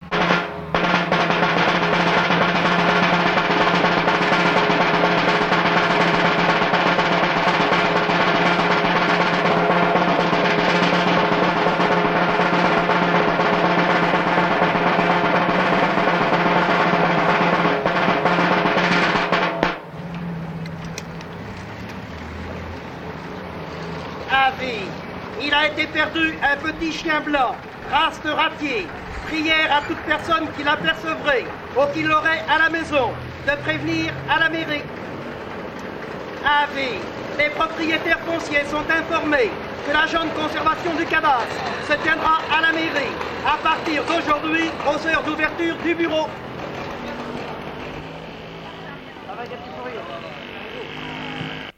Mémoires et Patrimoines vivants - RaddO est une base de données d'archives iconographiques et sonores.
garde-champêtre, tambour, officier public
Pièce musicale inédite